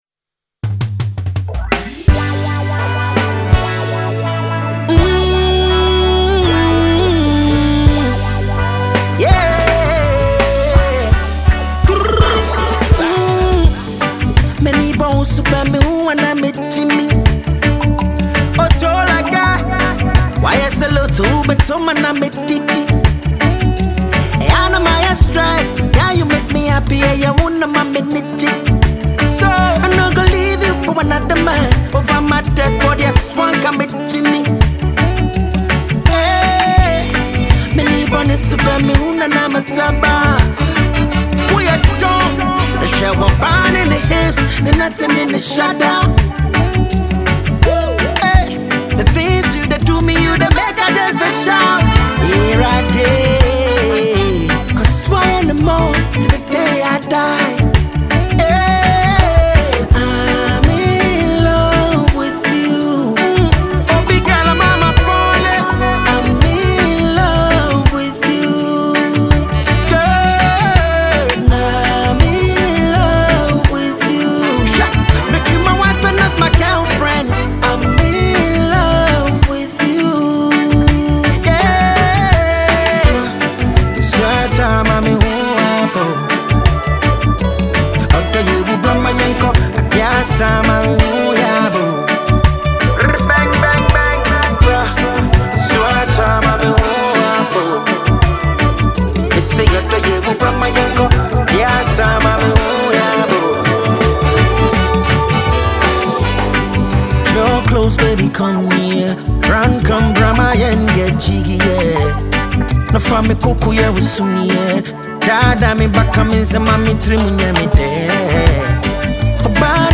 a Ghanaian dancehall act